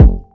Kick40.wav